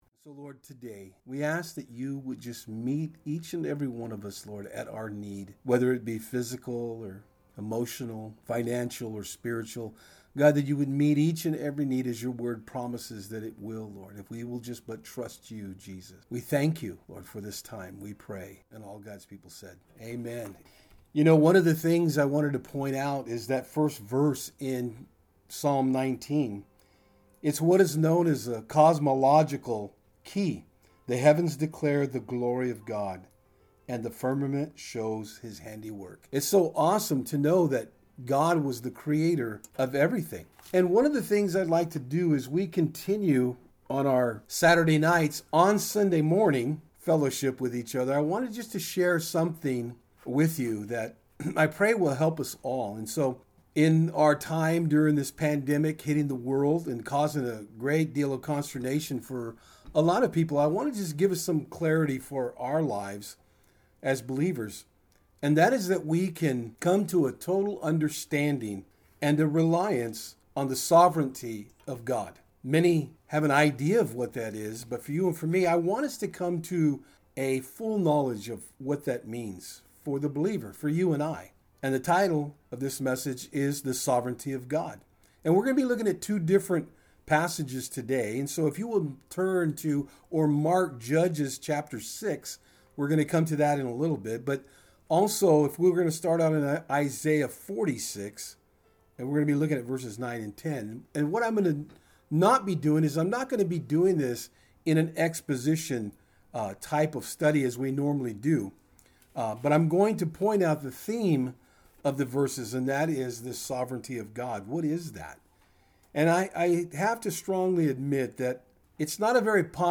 Service Type: Sundays @ Fort Hill